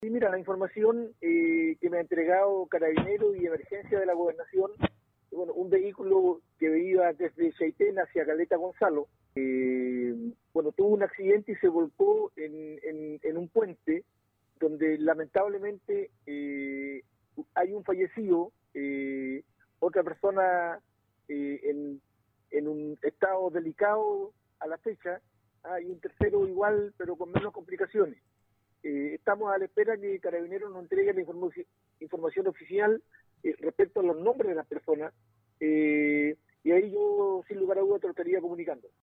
También el gobernador de Palena Osvaldo Oelckers confirmó la muerte del conductor del vehículo.